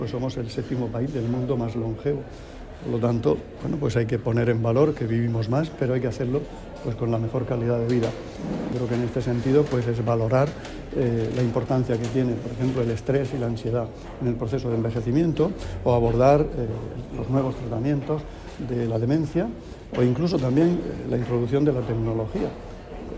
Declaraciones del consejero de Salud, Juan José Pedreño, sobre el simposio 'Más vida a los años'.